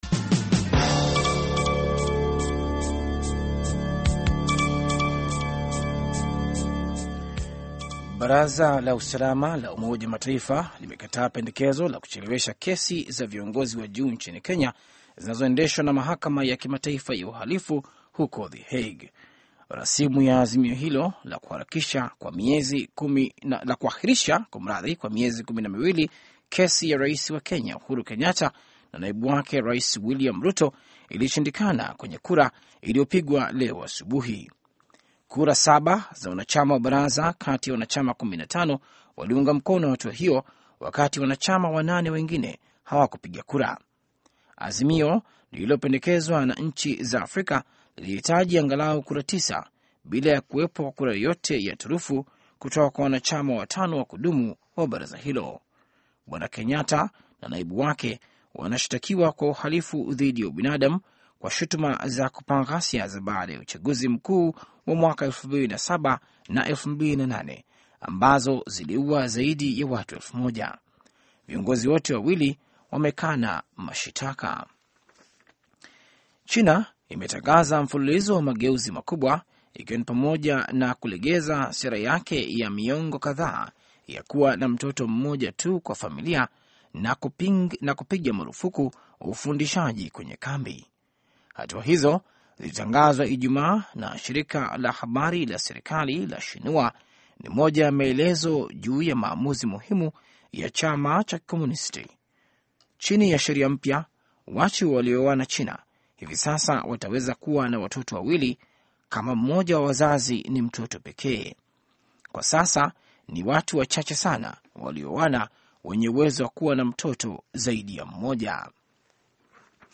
Taarifa ya Habari VOA Swahili - 6:05